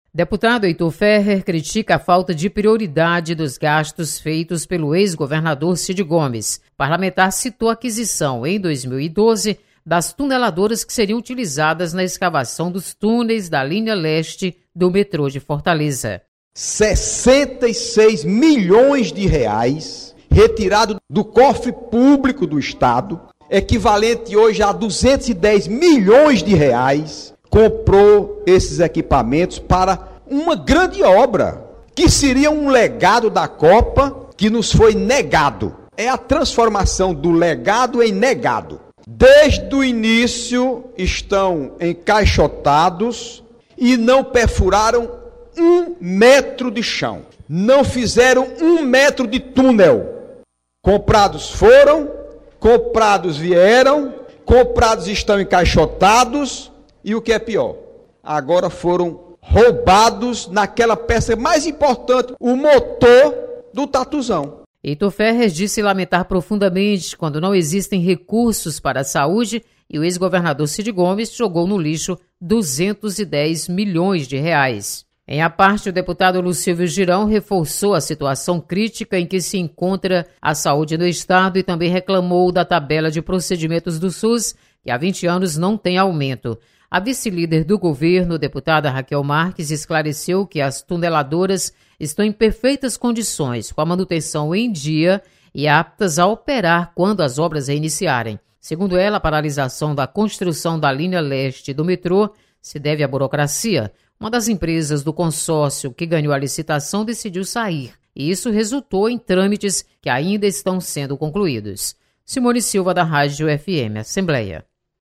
Deputado Heitor Férrer critica gastos da gestão Cid Gomes. Repórter